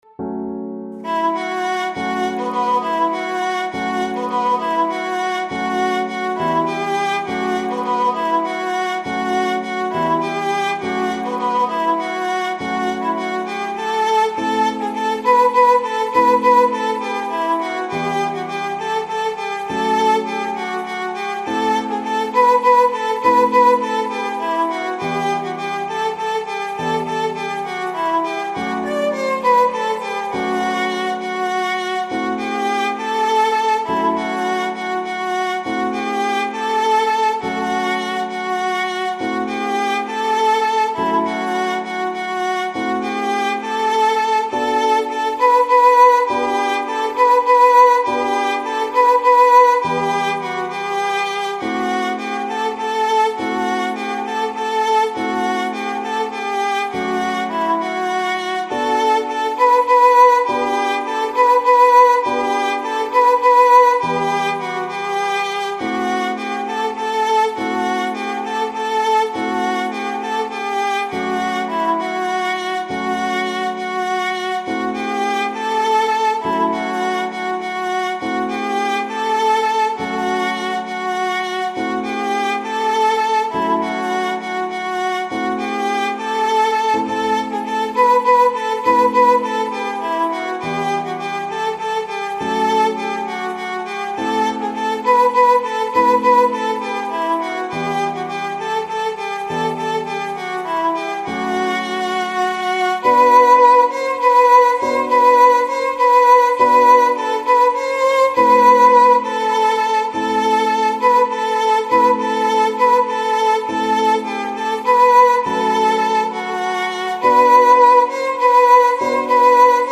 ویولون